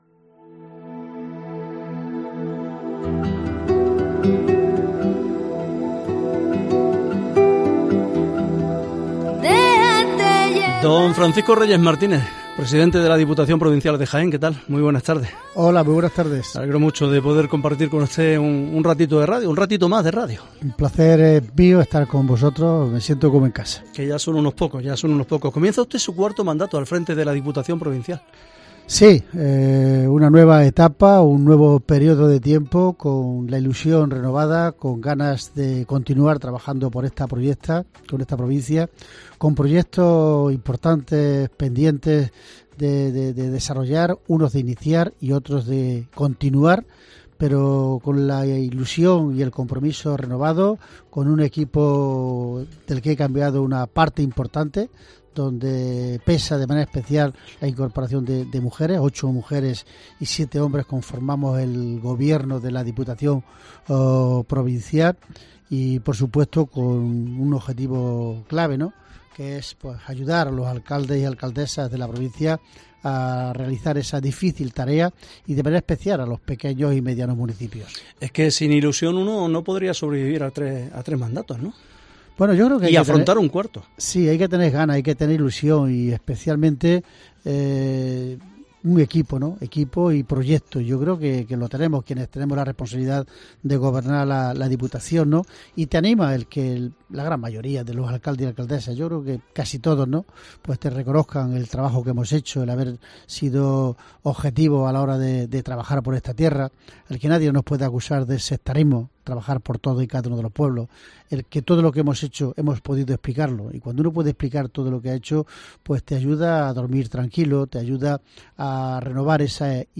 Hoy en COPE charlamos con Francisco Reyes, presidente de la Diputación de Jaén